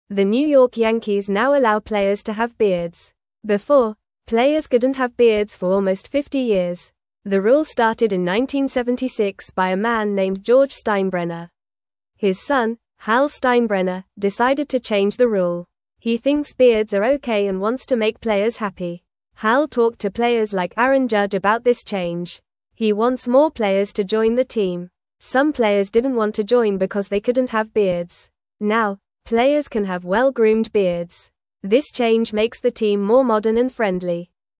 Fast
English-Newsroom-Beginner-FAST-Reading-Yankees-Players-Can-Now-Have-Beards-Again.mp3